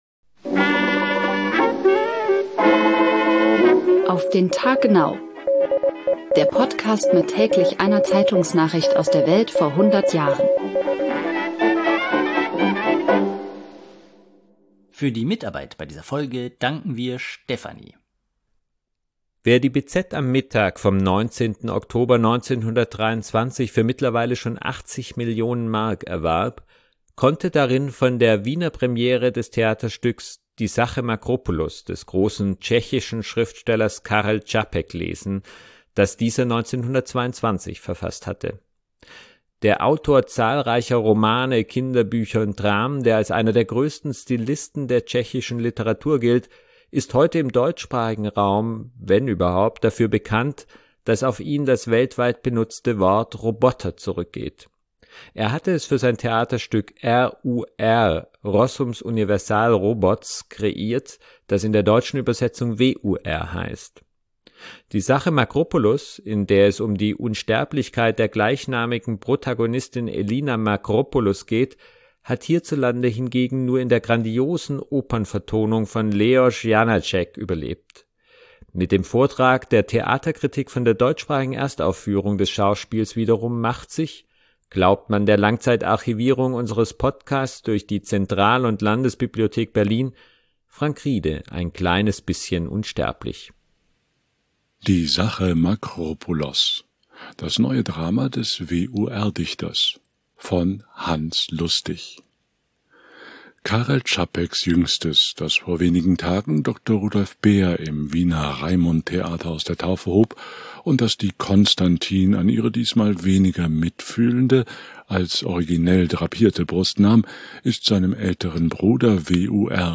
Mit dem Vortrag der Theaterkritik von der